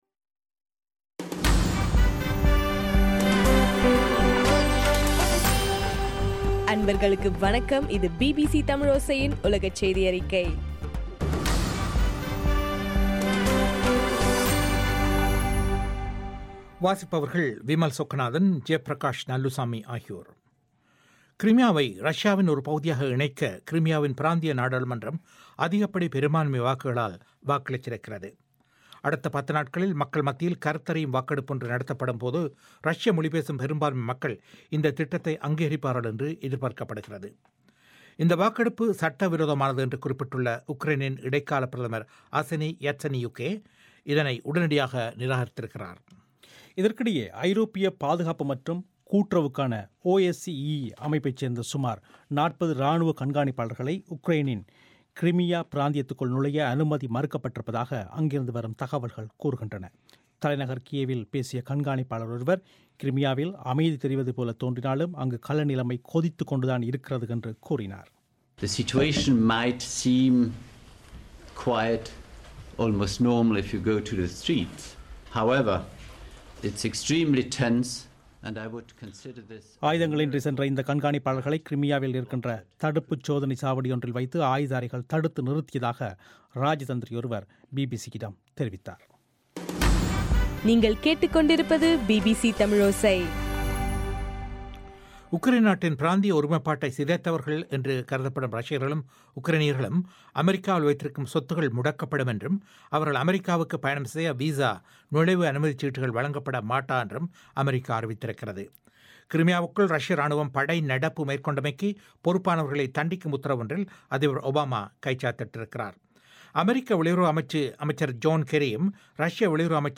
இலங்கையின் சக்தி எப்எம் வானொலியில் ஒலிபரப்பான பிபிசி தமிழோசையின் உலகச் செய்தியறிக்கை